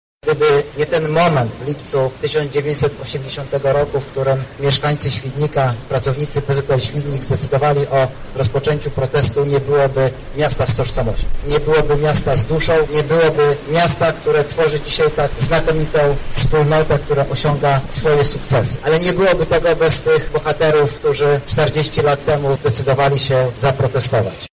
„Świdnik stał się miejscem, które umiłowało wolność” – mówi wiceminister aktywów państwowych Artur Soboń: